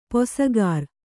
♪ posagār